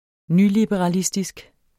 Udtale [ ˈny- ]